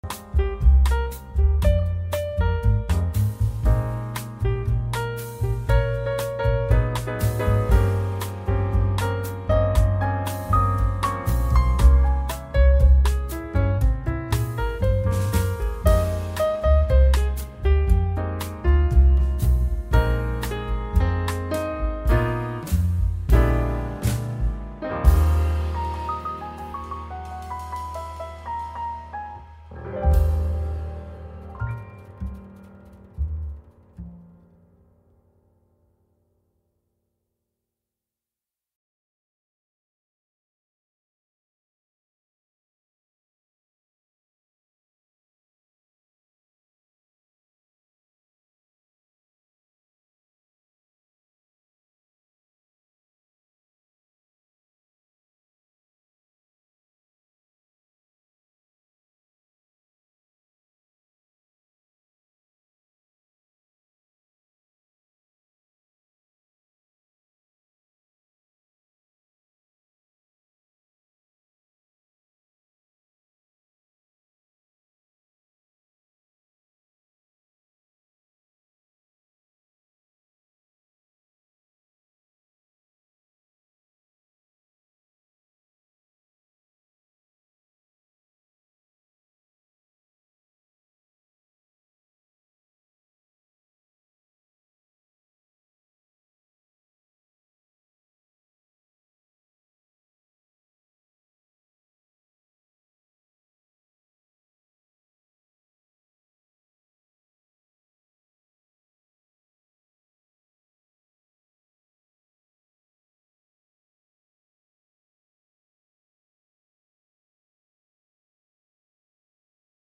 Estudio en Starbucks con ambiente real y sonidos de fondo